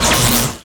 Magic_SpellImpact31.wav